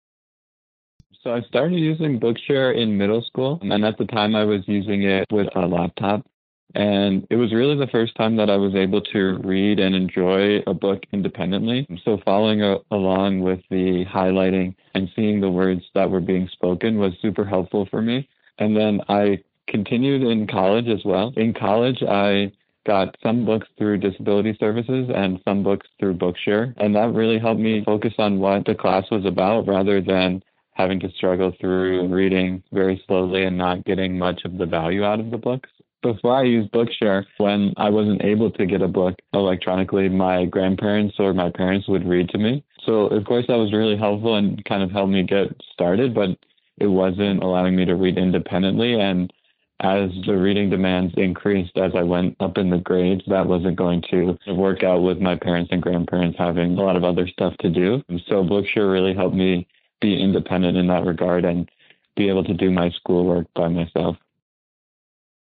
Bookshare Member